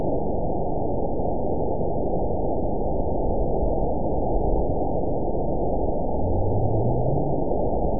event 912041 date 03/16/22 time 22:55:46 GMT (3 years, 2 months ago) score 8.13 location TSS-AB02 detected by nrw target species NRW annotations +NRW Spectrogram: Frequency (kHz) vs. Time (s) audio not available .wav